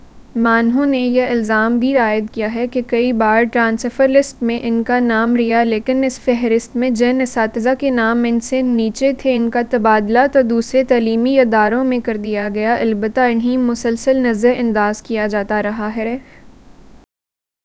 Spoofed_TTS/Speaker_05/105.wav · CSALT/deepfake_detection_dataset_urdu at main